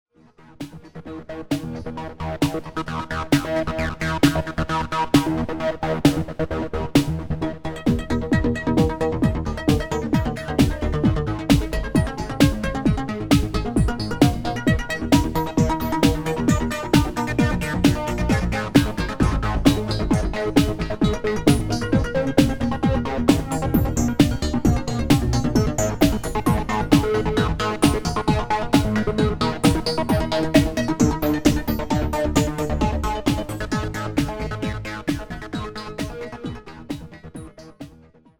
PYRATONE consumer applications DIY Audio PYRATONE VERSION II IN ONE SINGLE FPGA TO BUILD YOUR OWN SYNTHESIZERS listen to a demo pattern... read about the sound module ...
arpdemoremixed.wma